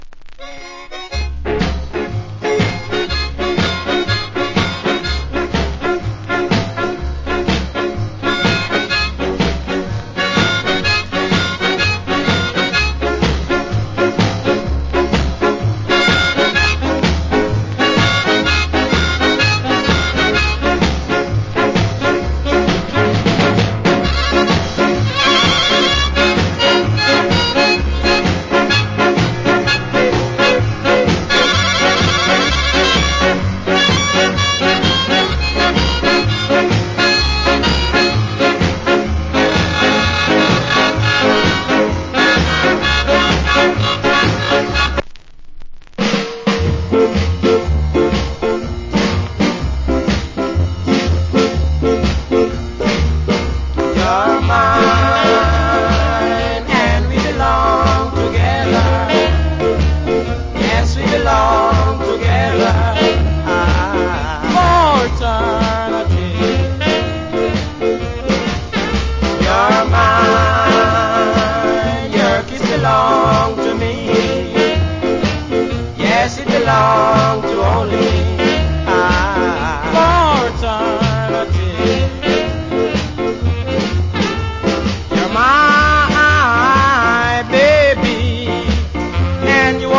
Nice Inst.